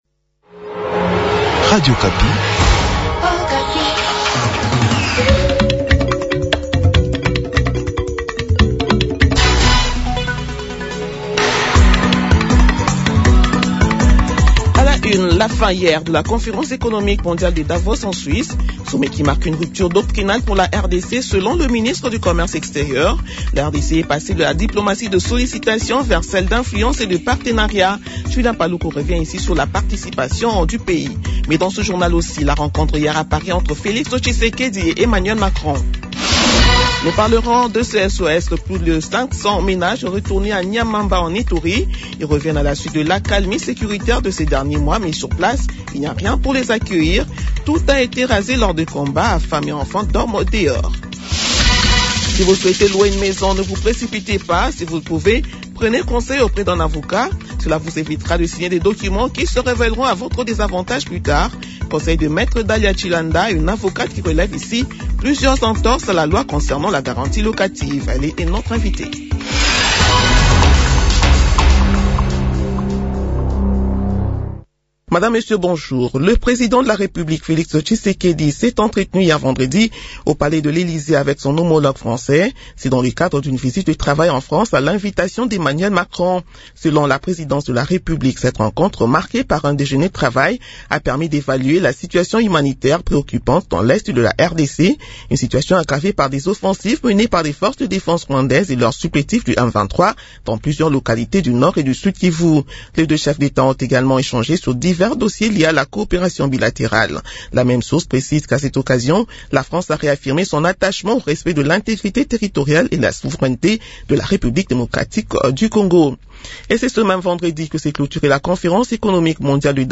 Journal de 7h du samedi 24 janvier